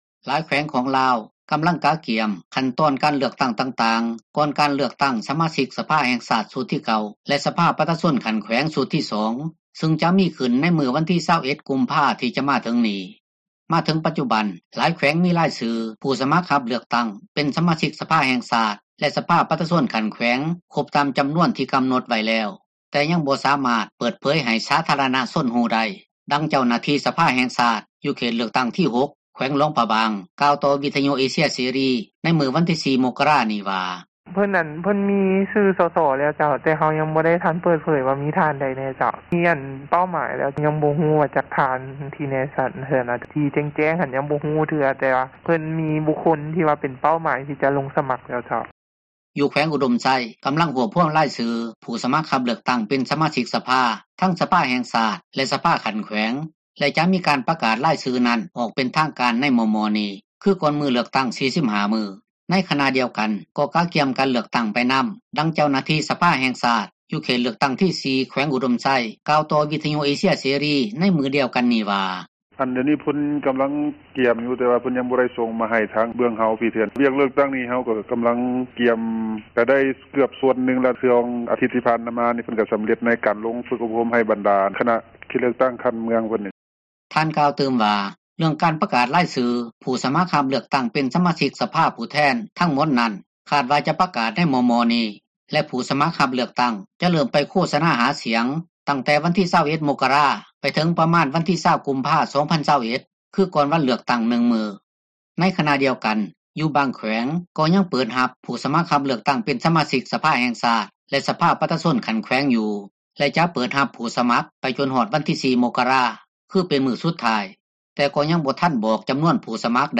ເວົ້າເຖິງການໂຄສະນາຫາສຽງ, ເຈົ້າໜ້າທີ່ສາທາຣະນະສຸກ ທ່ານນຶ່ງ ກ່າວວ່າ, ສໍາລັບການຫາສຽງກ່ອນການເລືອກຕັ້ງ ຂອງບັນດາ ຜູ້ສະມັກຮັບເລືອກຕັ້ງ ແລະການໃຊ້ສິດປ່ອນບັດ ເລືອກຕັ້ງ ຂອງປະຊາຊົນ ແຕ່ລະເຂດເລືອກຕັ້ງ, ຈະດໍາເນີນໄປພາຍໃຕ້ມາຕການ ປ້ອງກັນ ການແຜ່ຣະບາດຂອງເຊື້ອພຍາດໂຄວິດ-19, ໂດຍຈະບໍ່ໃຫ້ມີການເຕົ້າໂຮມກັນຫລາຍ ເກີນໄປ, ດັ່ງທີ່ທ່ານກ່າວຕໍ່ ວິທຍຸ ເອເຊັຽເສຣີວ່າ:
ແລະຊາວໜຸ່ມລາວຄົນນຶ່ງ ກໍເວົ້າວ່າ ຕົນເອງກໍກຽມພ້ອມແລ້ວ, ສໍາລັບການໄປໃຊ້ສິດປ່ອນບັດເລືອກຕັ້ງຢູ່ເຂດເລືອກຕັ້ງຂອງຕົນ, ໃນມື້ວັນທີ 21 ກຸມພາ ທີ່ຈະມາເຖິງນີ້ ແລະວ່າ ຈະໃຊ້ສິດເລືອກໃຫ້ໄດ້ຜູ້ແທນທີ່ດີ ແລະເປັນຄວາມຫວັງຂອງປະຊາຊົນ: